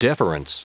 Prononciation du mot deference en anglais (fichier audio)
Prononciation du mot : deference